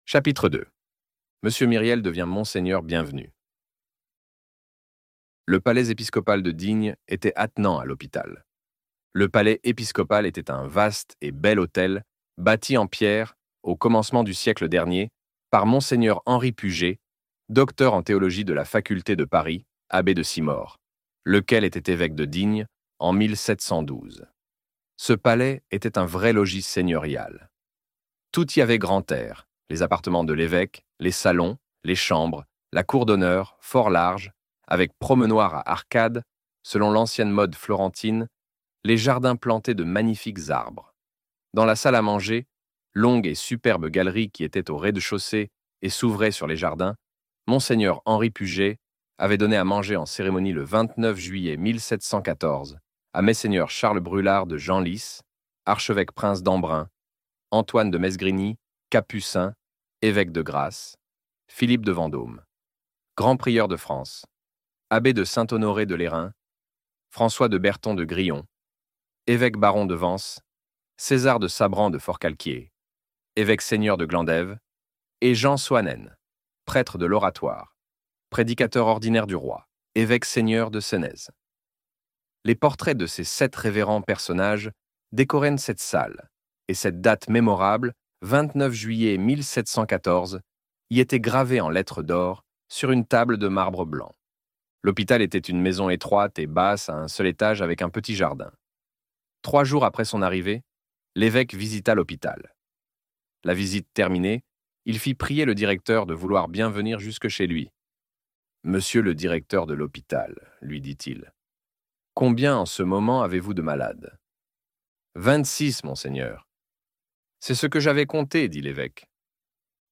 Les Misérables - Partie 1 - Livre Audio